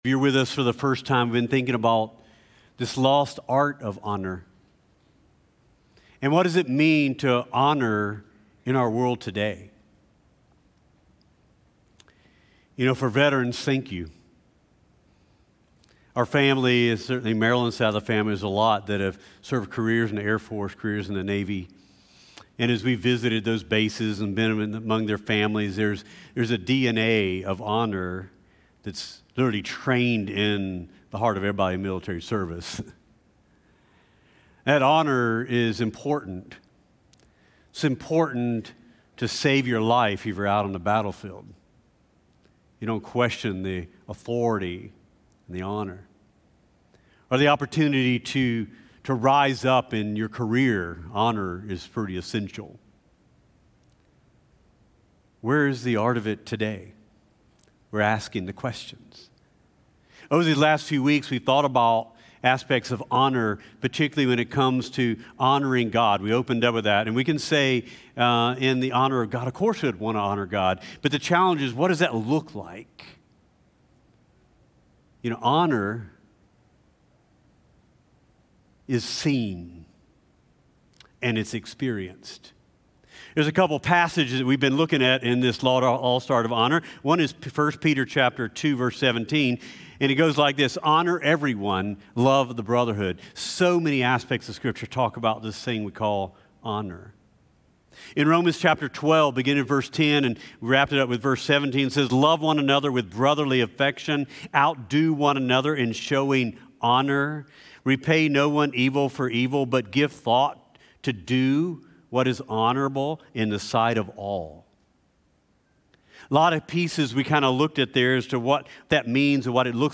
CtK-Sermon.mp3